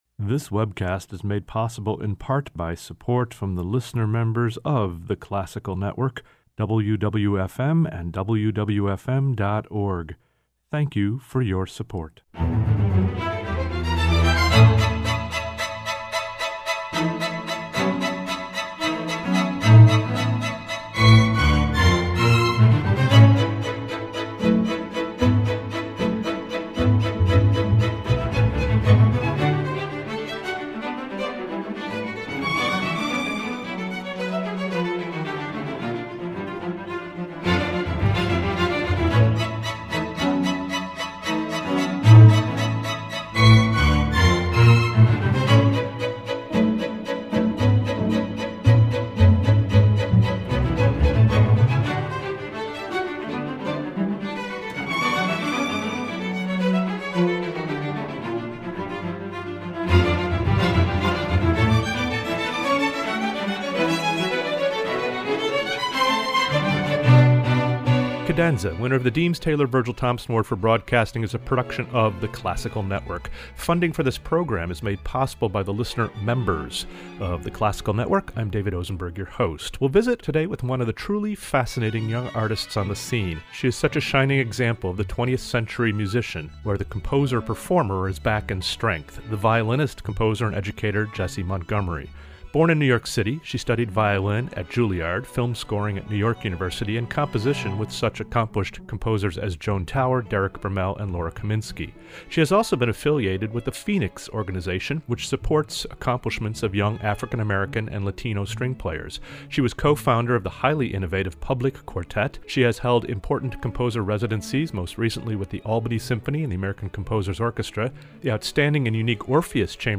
Cadenza - Jessie Montgomery, composer/violin
Interviews